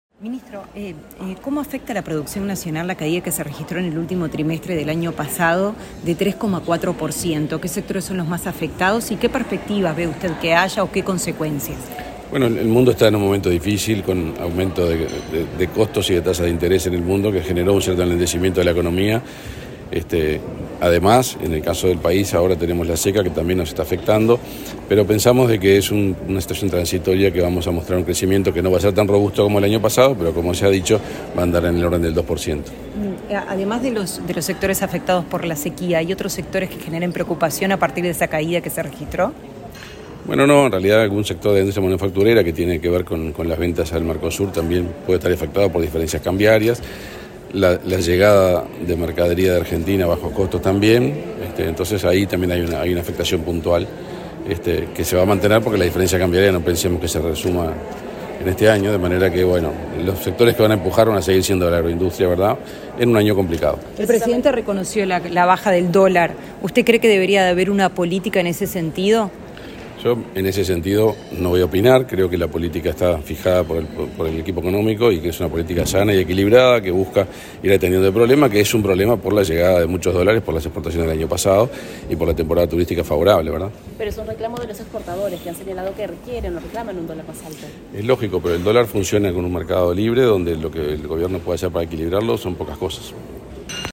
Declaraciones del ministro de Industria, Omar Paganini